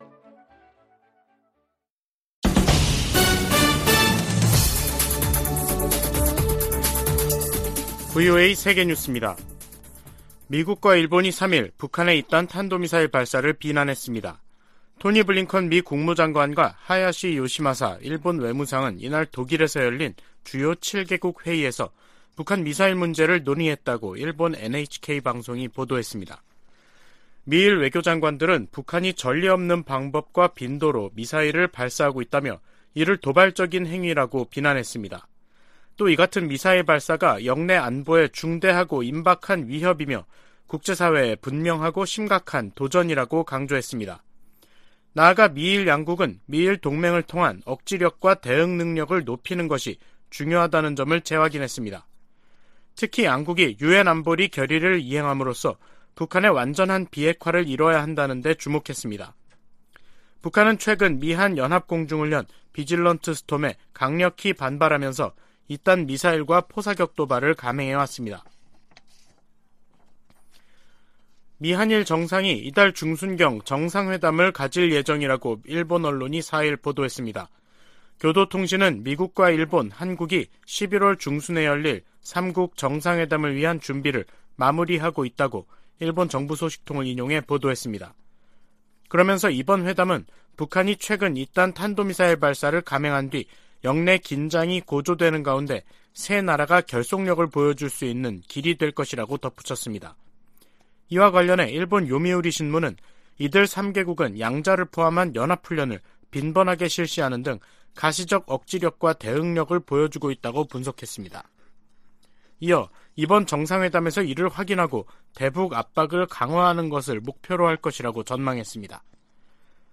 VOA 한국어 간판 뉴스 프로그램 '뉴스 투데이', 2022년 11월 4일 3부 방송입니다. 북한 김정은 정권이 오늘 대규모 군용기를 동원한 무력 시위를 하자 한국이 이에 대응해 스텔스 전투기 등 80여를 출격시키는 등 한반도에서 긴장이 계속되고 있습니다. 미국과 한국 국방장관이 미국 전략자산을 적시에 한반도 전개하는 방안을 강구하고 핵우산 훈련도 매년 실시하기로 합의했습니다.